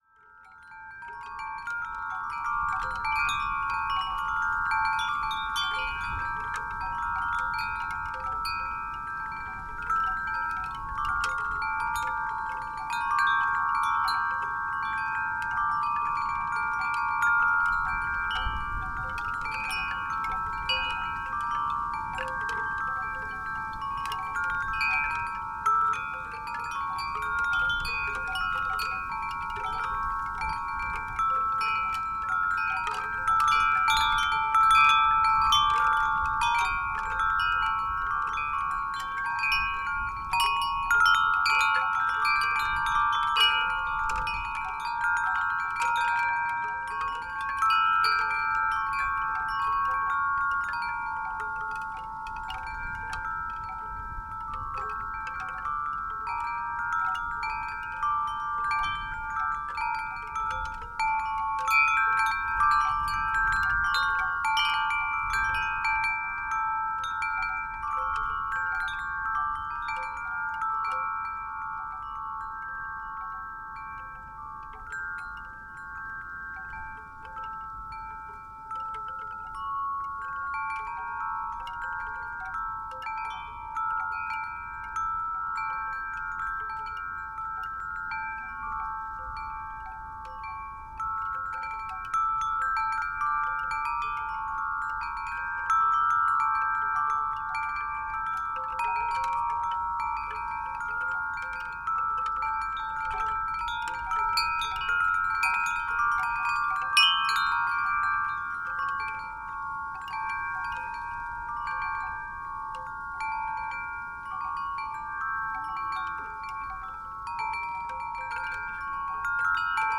Wind Chimes, Teign Gorge near Castle Drogo - Gypsy Soprano - Bamboo - excerpt
bamboo Castle-Drogo chimes Devon England field-recording Gypsy Music-of-the-Spheres sound effect free sound royalty free Music